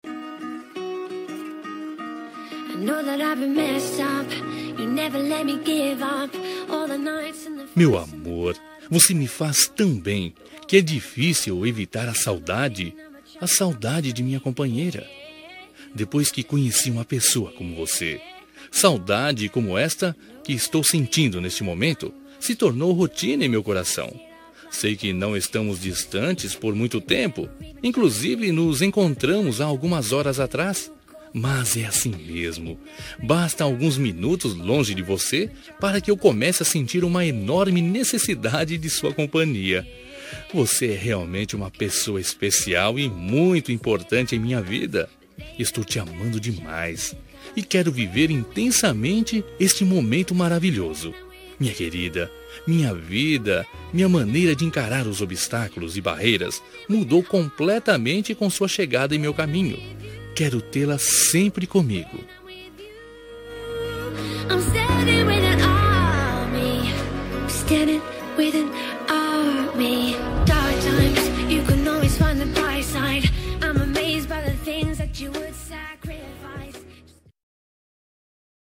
Telemensagem de Saudades – Voz Masculina – Cód: 443
443-saudades-masc-1.m4a